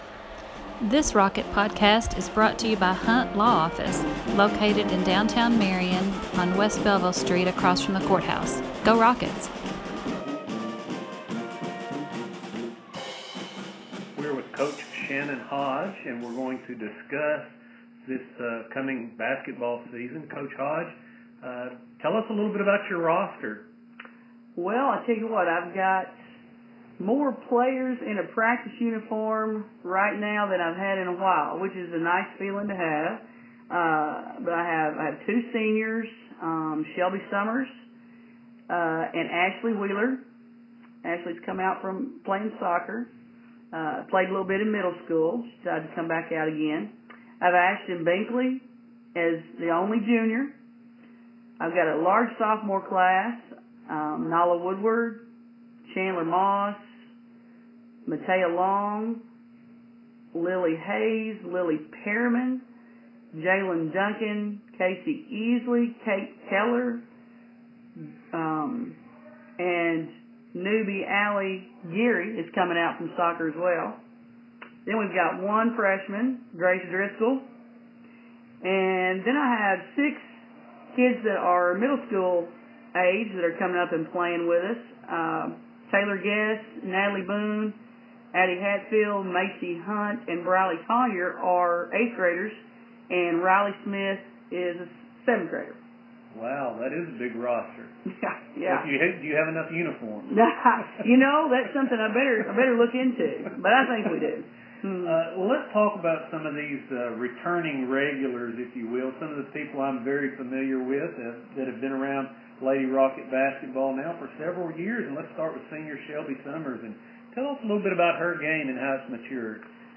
PreSeason Interview